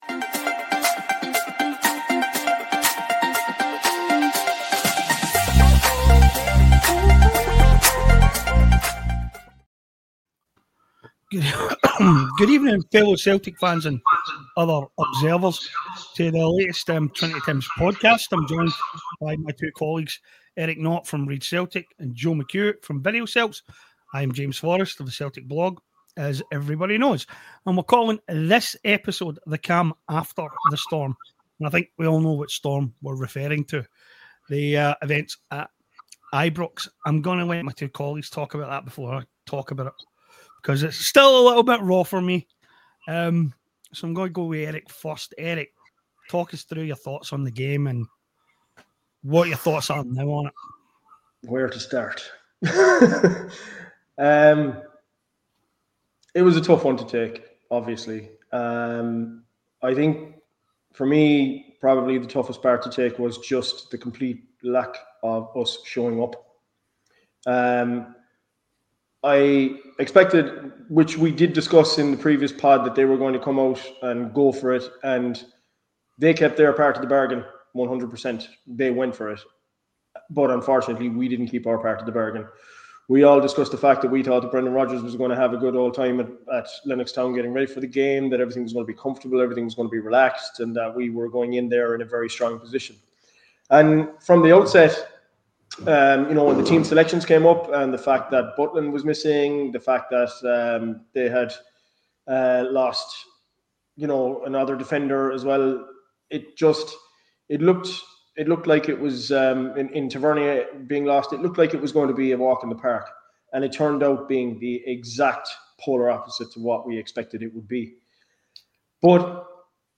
Three Celtic bloggers.